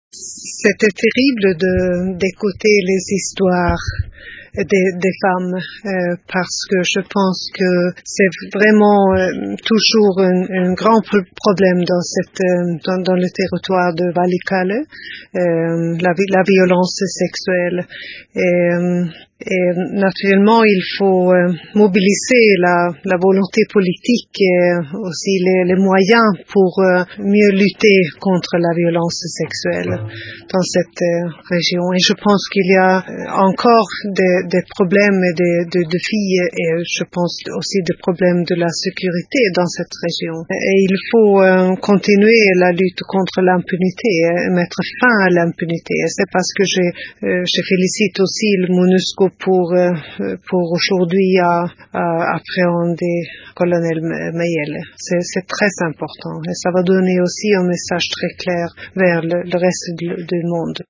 Se confiant à Radio Okapi, Mme Margot Wallstrom a appelé à plus des moyens et de volonté politique pour mieux lutter contre ce drame.